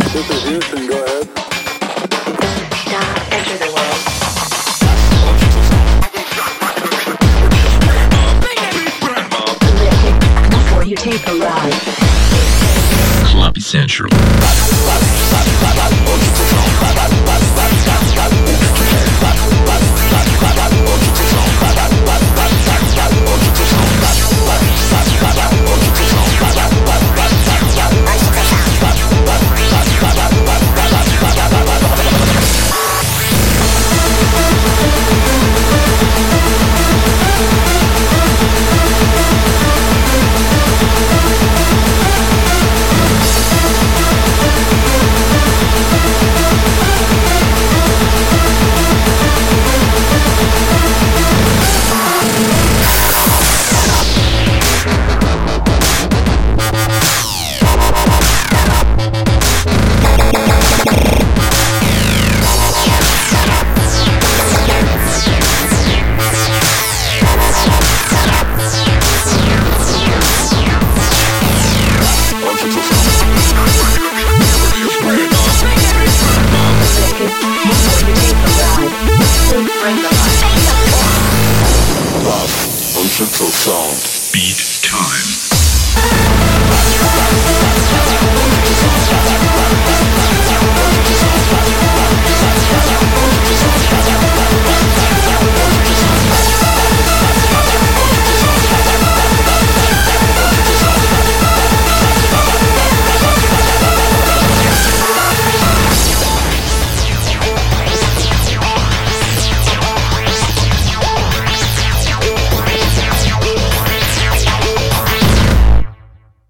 BPM200
Audio QualityPerfect (High Quality)
GABBA